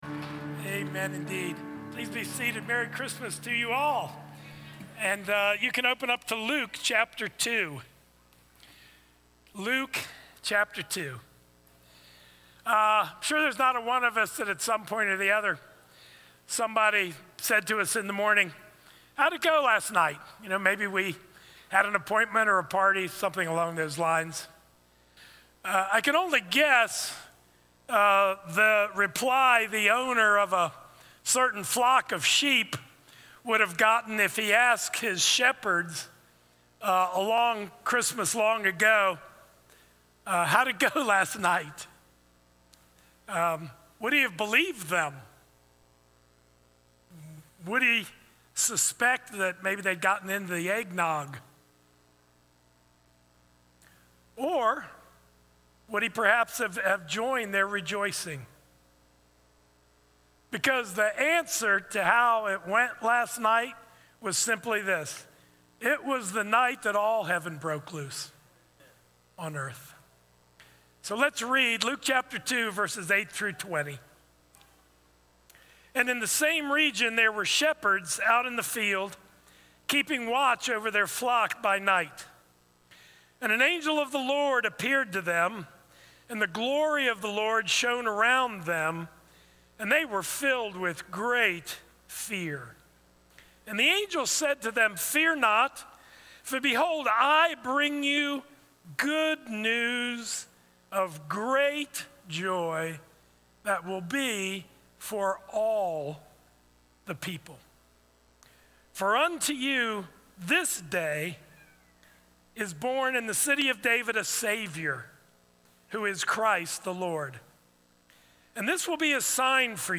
The Shepherd’s First Christmas – Stand Alone Sermons | Crossway Community Church